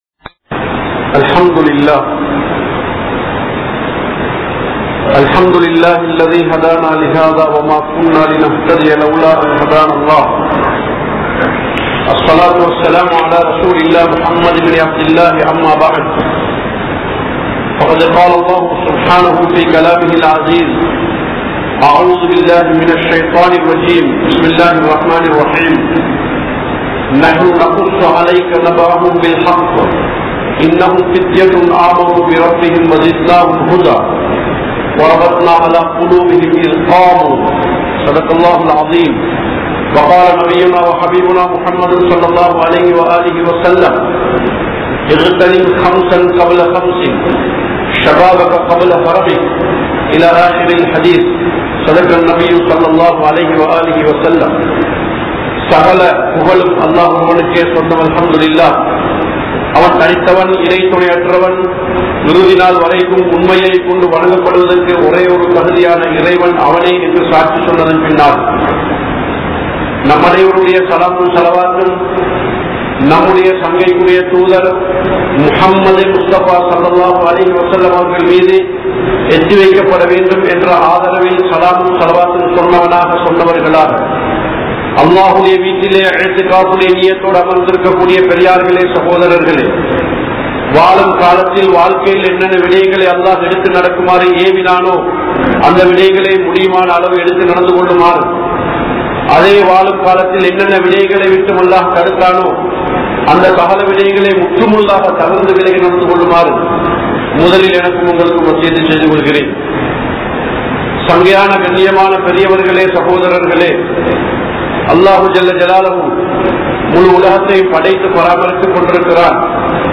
Paavaththitku Palakka Patta Indraya Vaalifarhal (பாவத்திற்கு பழக்கப்பட்ட இன்றைய வாலிபர்கள்) | Audio Bayans | All Ceylon Muslim Youth Community | Addalaichenai